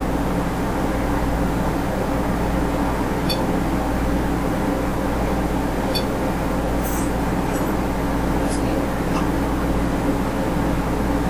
This is another voice recorded during a "static" EVP session, where we had left several recorders running while we waited outside of the house in the external building. The recorder that captured this EVP was on the bed in the master bedroom upstairs.
First you'll hear two "beep" sounds, which is our EMF "trigger prop" dog being triggered by something in the adjacent kids' room, which in itself is unexplained. But then the best part - a clear young female voice speaking a complete sentence that I believe could be a "residual voice" - a replay of a past moment in the home life. Young female speaks upstairs!